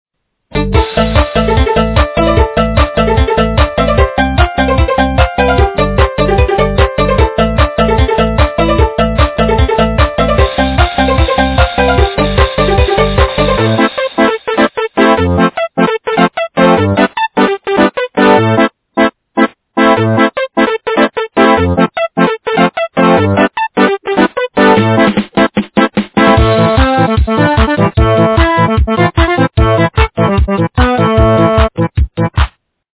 качество понижено и присутствуют гудки